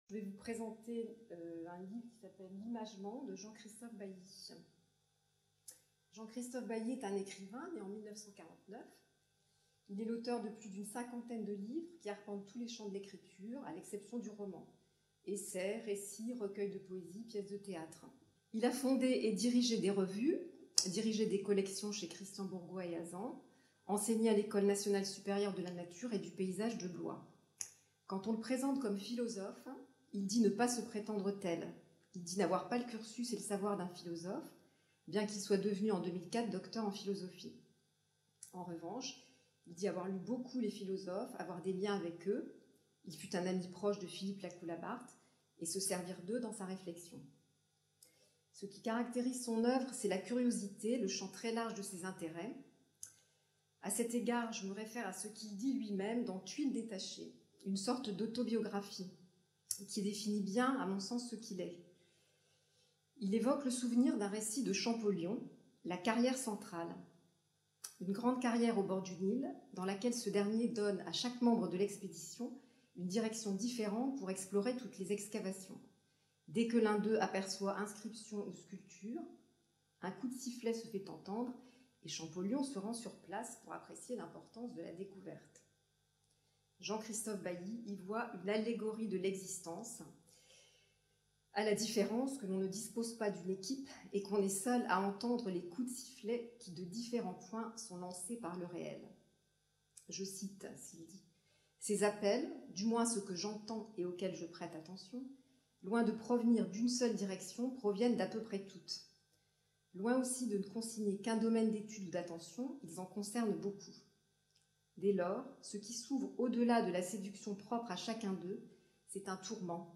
Atelier Présentation de livres contemporains de philosophie